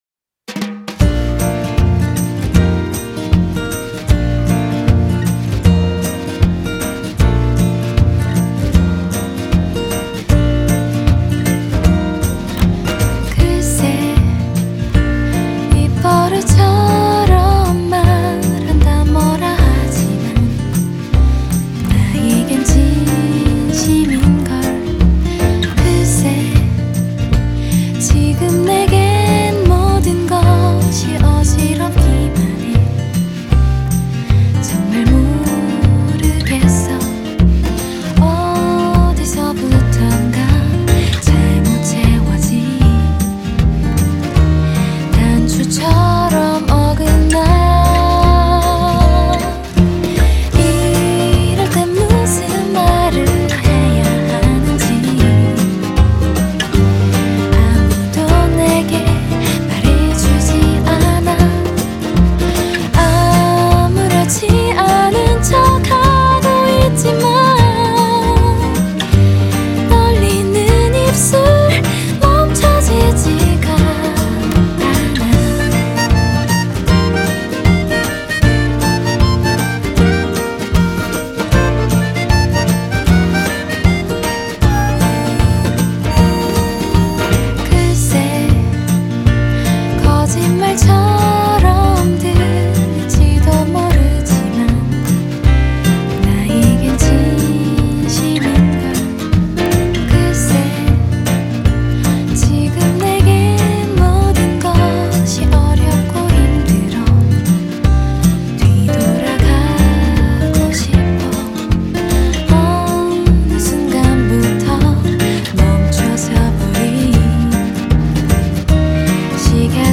전체적으로 Simon and Garfunkel이 떠오르는 포크, 컨트리의 분위기에다
중반 하모니카 애드립 이후에 기타와 피아노가 레게 리듬을 컴핑하는 편곡으로서
우리가 예상한 것보다 만돌린의 고음 성분이 너무 강렬해서
결국 이런 저런 시도 끝에 만돌린의 고음역대 줄 몇 개를 빼고 녹음을 했습니다.
결국 귀에 익숙해져버린 미디 시퀸싱한 하모니카 애드립을 그대로 사용했습니다.